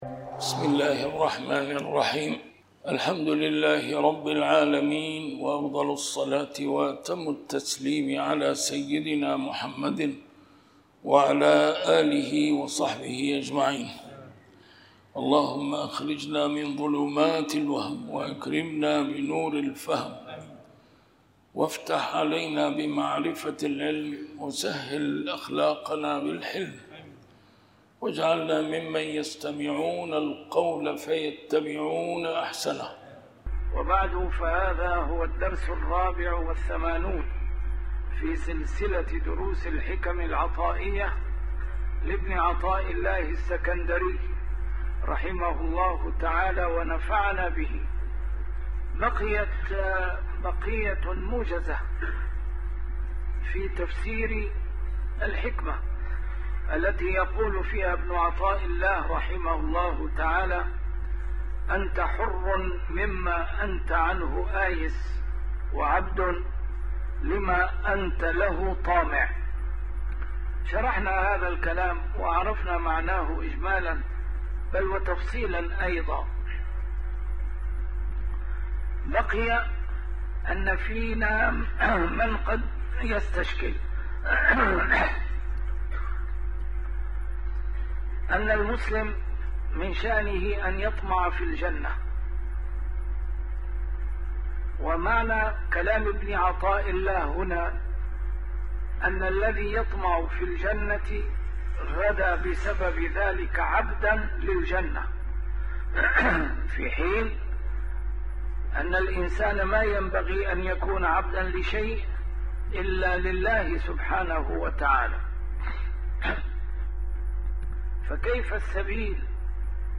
A MARTYR SCHOLAR: IMAM MUHAMMAD SAEED RAMADAN AL-BOUTI - الدروس العلمية - شرح الحكم العطائية - الدرس رقم 84 شرح الحكمة 62+63